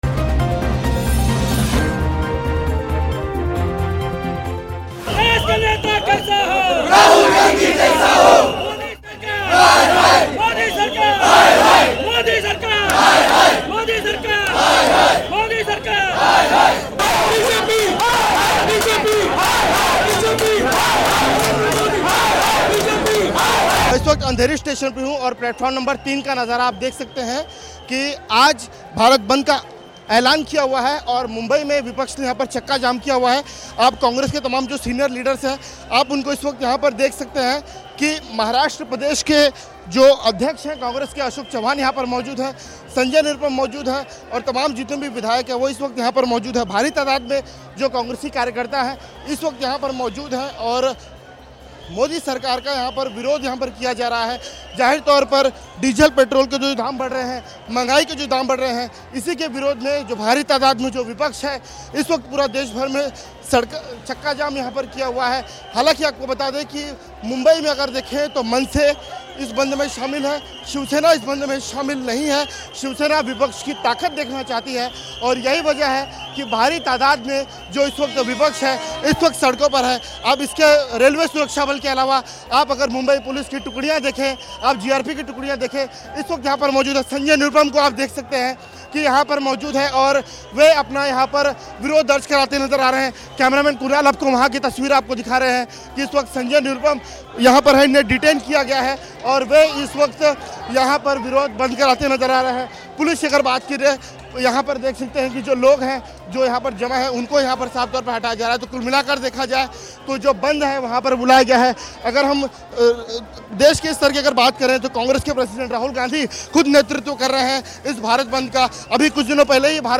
न्यूज़ रिपोर्ट - News Report Hindi / भारत बंद, नरेंद्र मोदी सरकार निकम्मी है, राहुल गांधी बनेंगे पीएम - लगे नारे